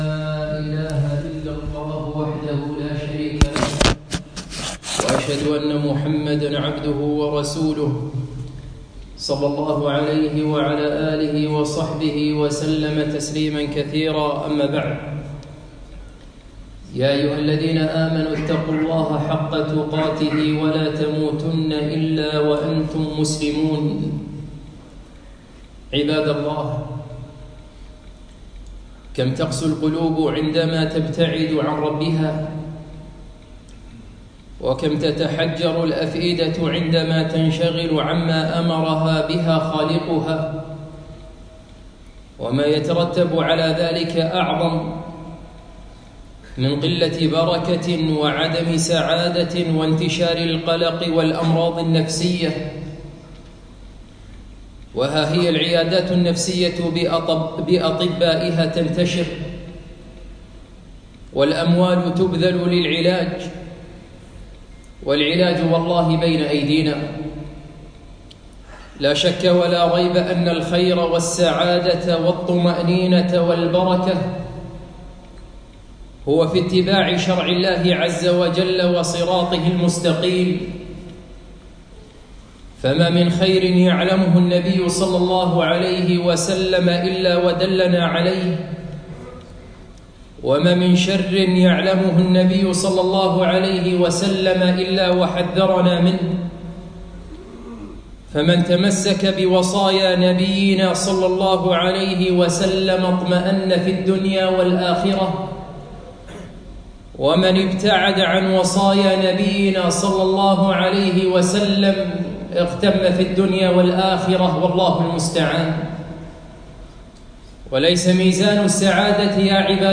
خطبة - وصايا نبوية